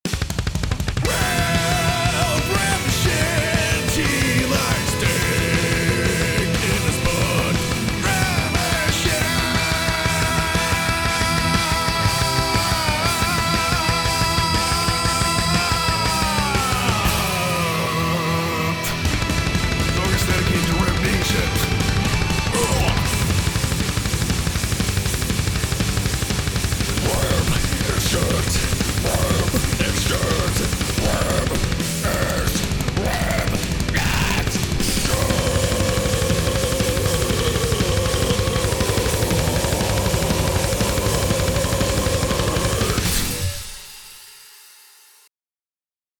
Here's your metal right here folks